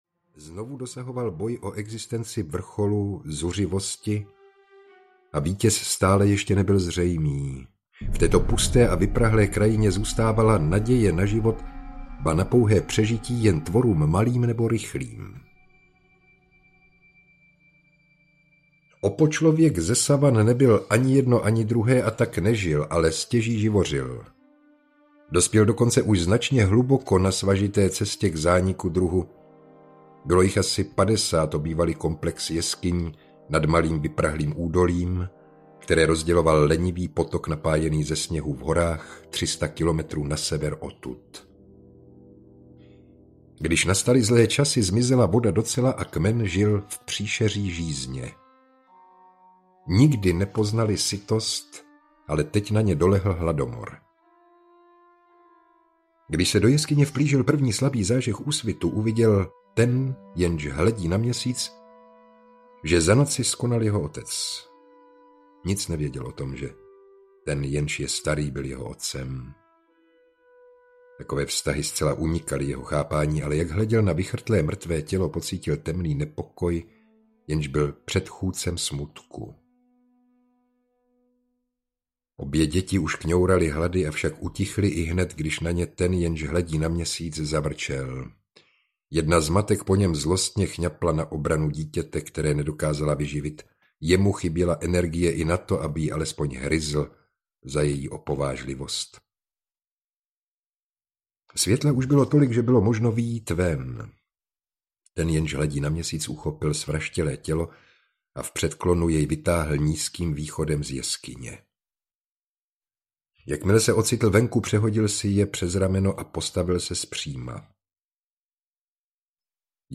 2001: Vesmírná odysea audiokniha
Audioknižní operní opus, který posouvá hranice mluveného slova.
Ukázka z knihy